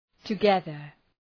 Προφορά
{tə’geðər}